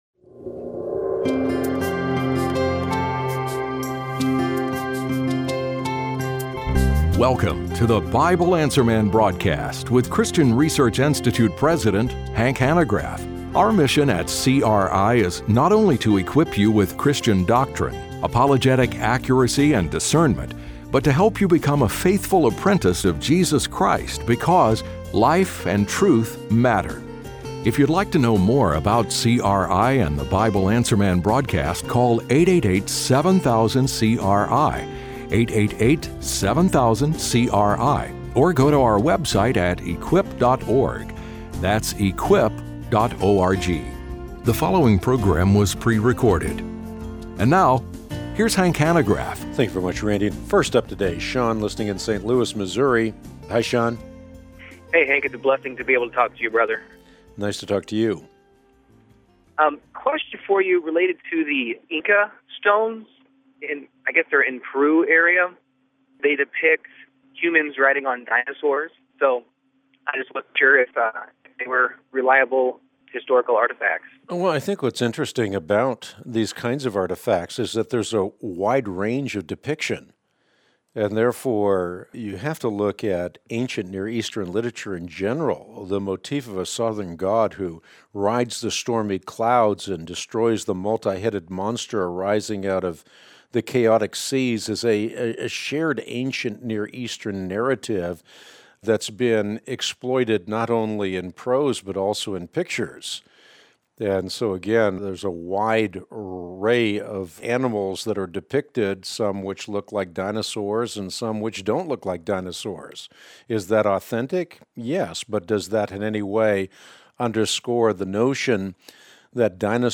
Q&A: Christ’s Return, Letting Go of Hate, and Levitical Laws - Christian Research Institute